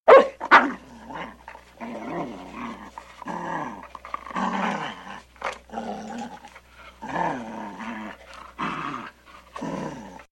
На этой странице собраны звуки собак, играющих с игрушками: лай, повизгивание, рычание и другие забавные моменты.
Звук собаки на цепи играющей с костью